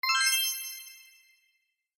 sparkle.mp3